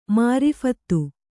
♪ māriphattu